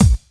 BD DM2-06.wav